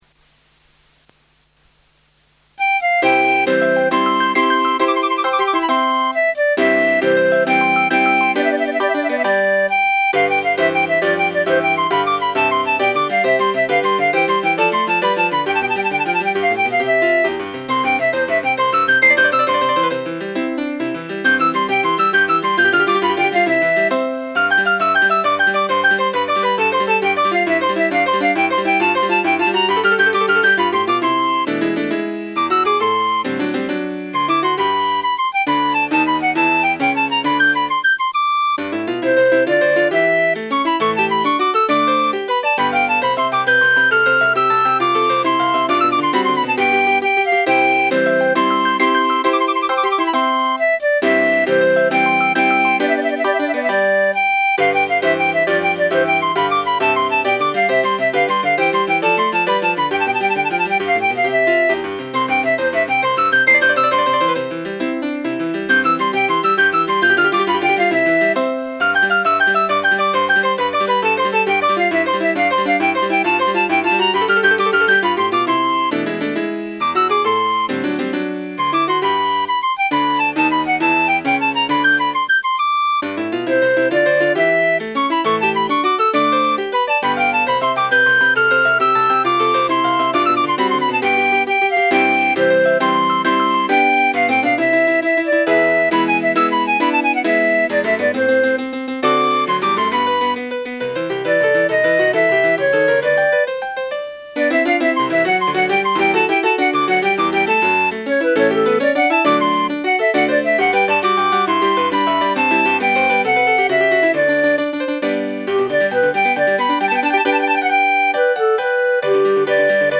Sonate voor fluit en een of ander klavier.
For flute and some keyboard.